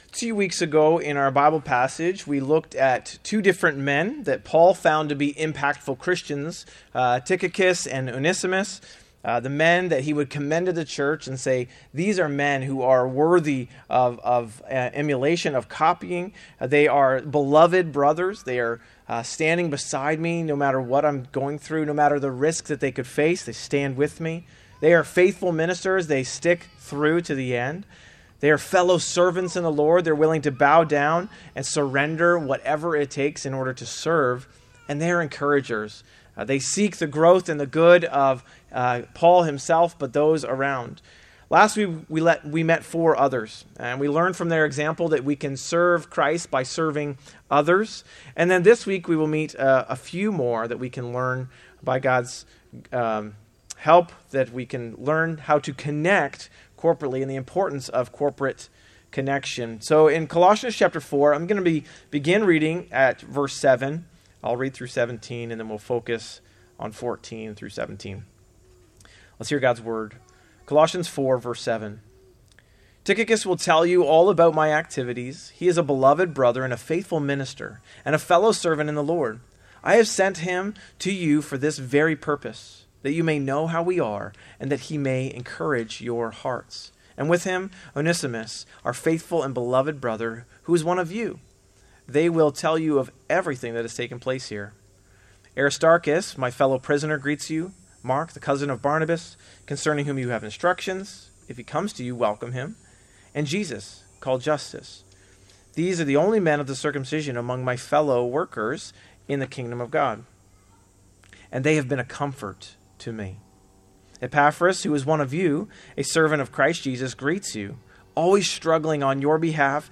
Sermons | West Lorne Baptist Church